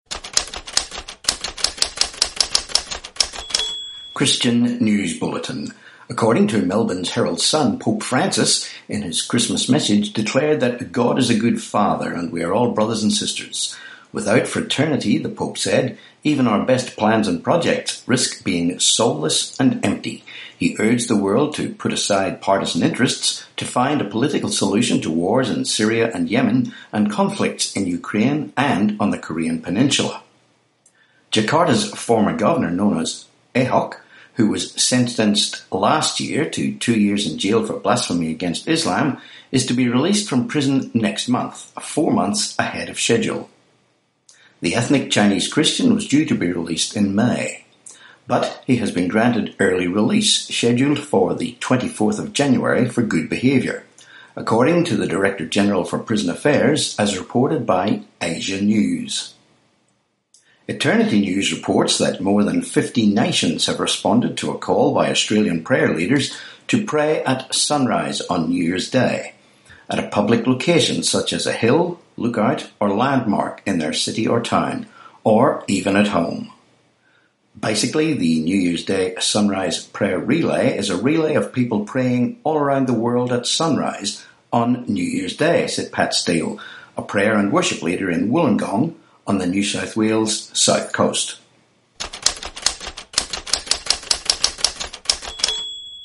30Dec18 Christian News Bulletin